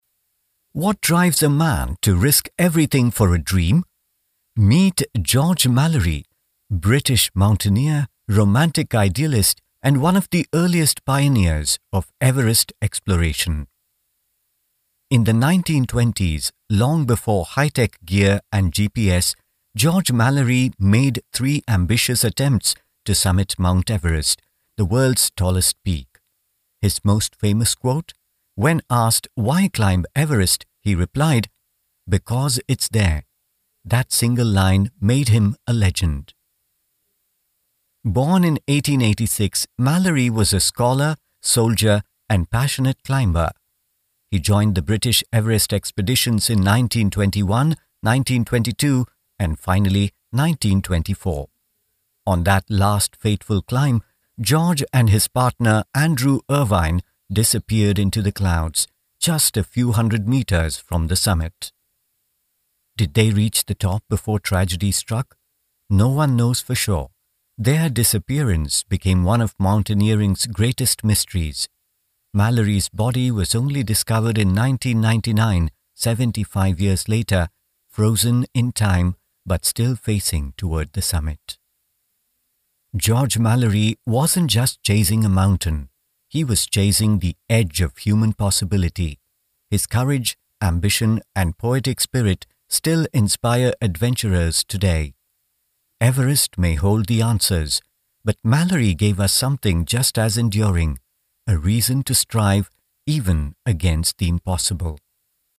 Male
Warm, friendly, soothing voice with a good balance of bass and clear high end.
Middle-aged Neutral English accent tilted towards British/Indian with clear enunciation, and judicious pace.
Explainer Videos
1202Explainer.mp3